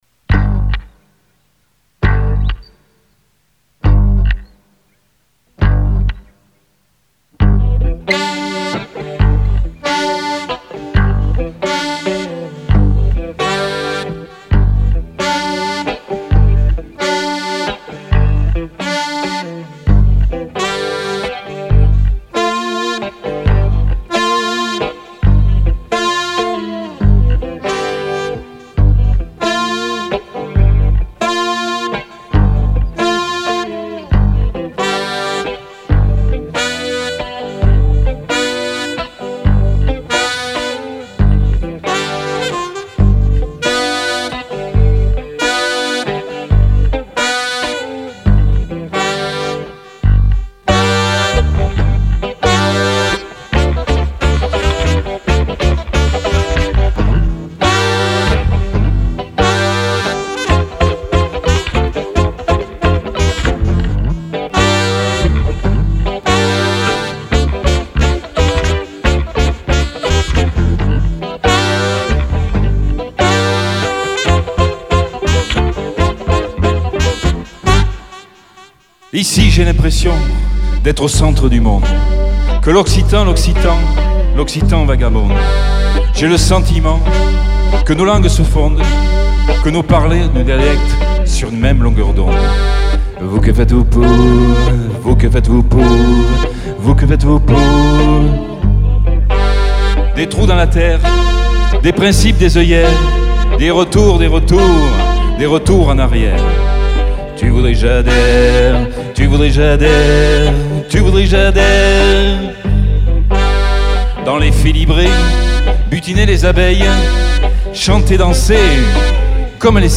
du funk en version acoustique !
et en public s'il vous plait en 2021